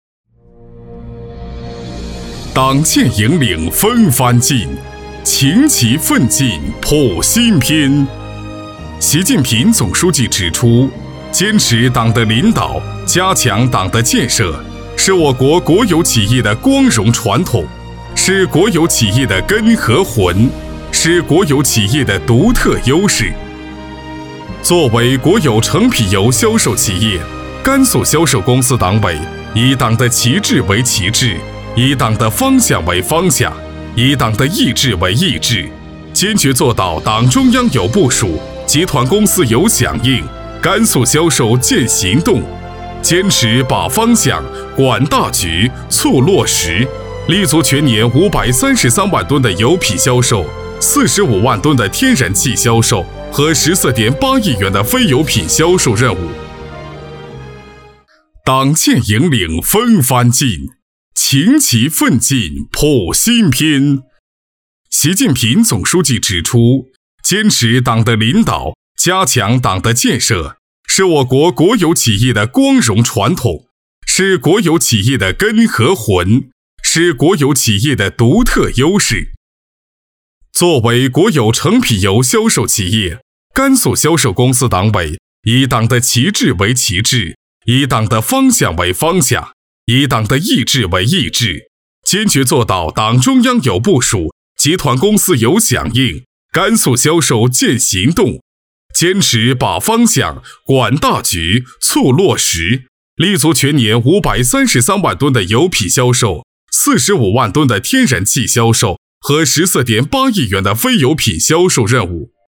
男41号配音师
声音浑厚、大气、可年轻、可搞怪，擅长专题片，宣传片，汇报片，纪录片，解说旁白等